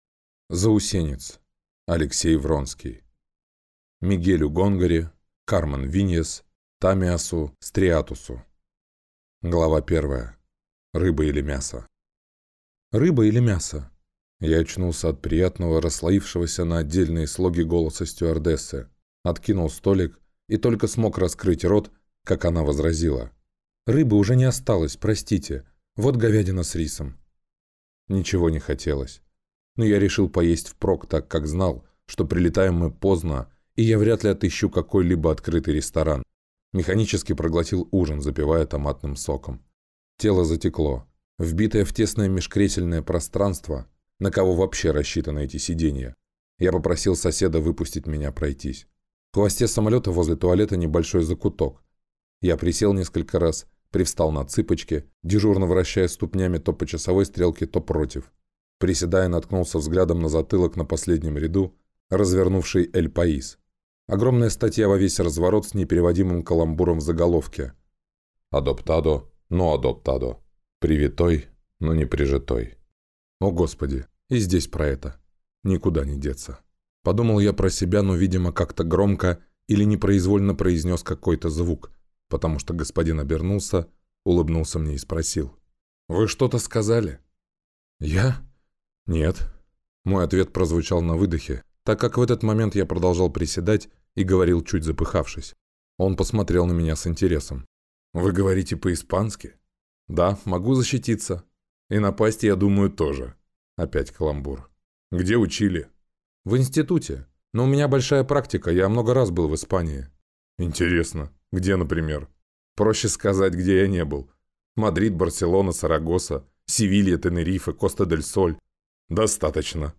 Аудиокнига Заусенец | Библиотека аудиокниг